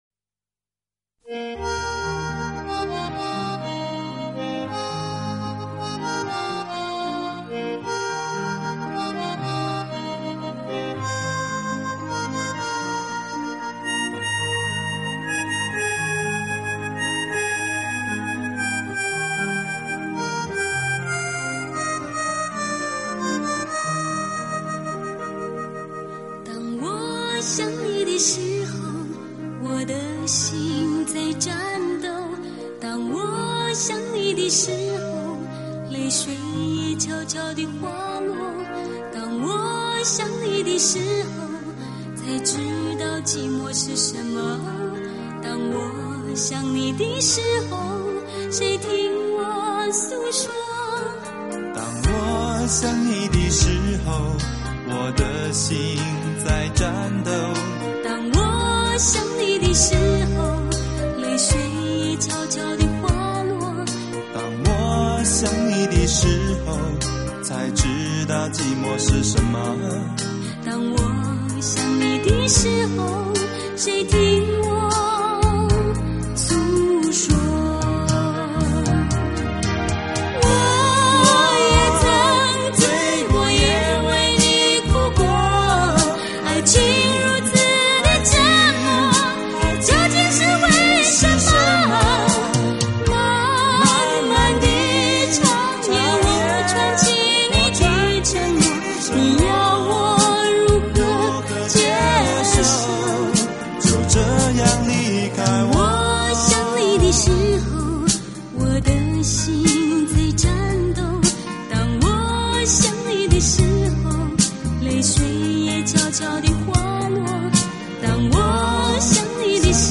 音质非常好